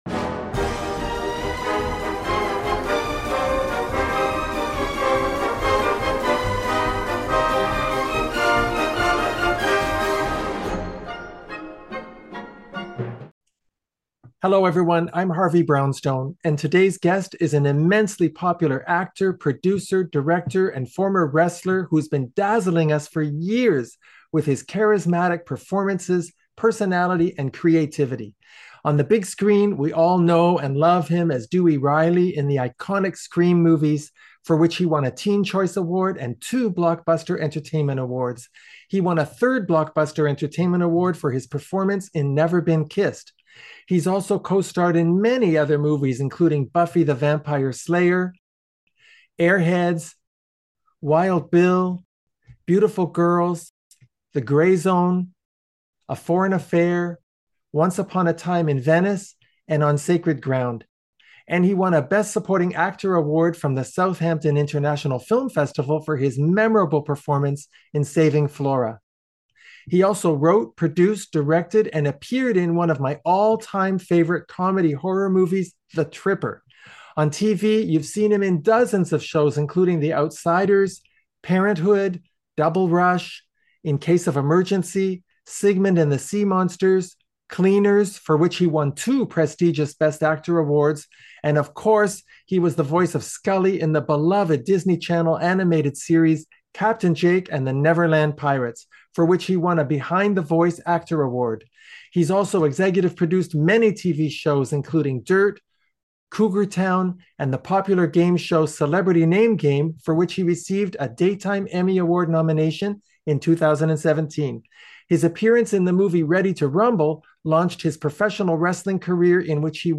Interviews David Arquette, Renowned Actor, Producer, Director And Former Wrestler